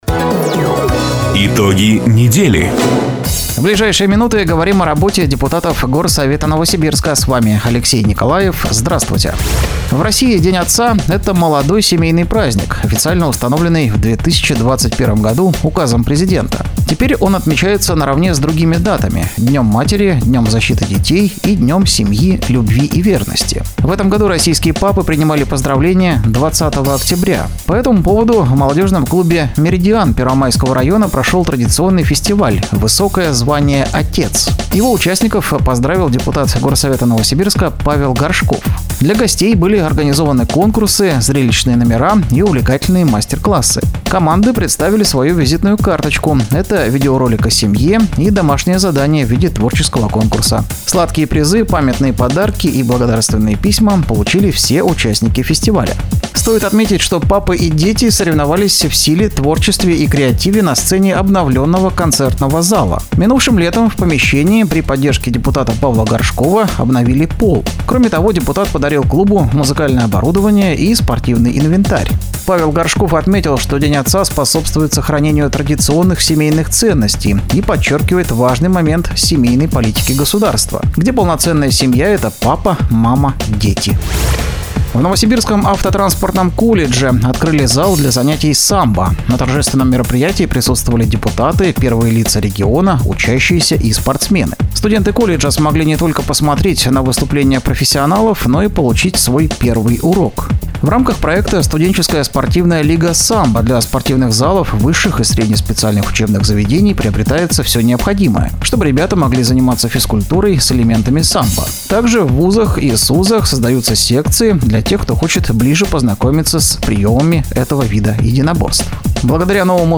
Запись программы "Итоги недели", транслированной радио "Дача" 26 октября 2024 года.